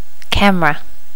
Normalize all wav files to the same volume level.
camera.wav